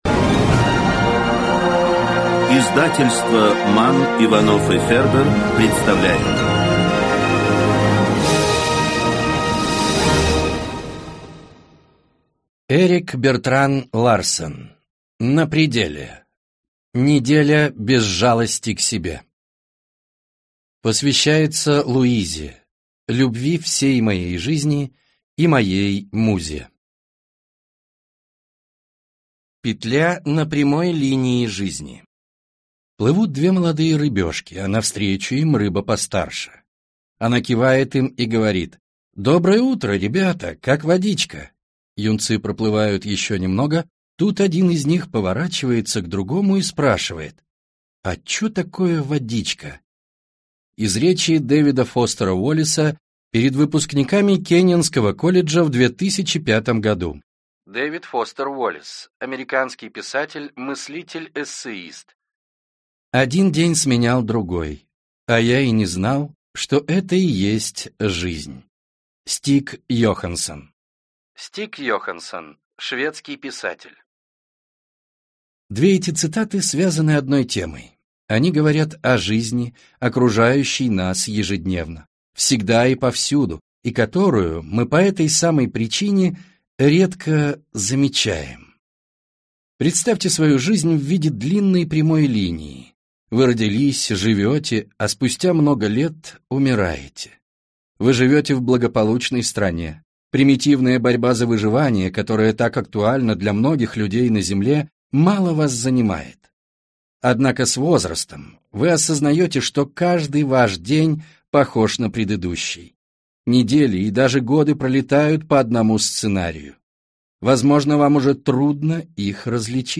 Аудиокнига На пределе.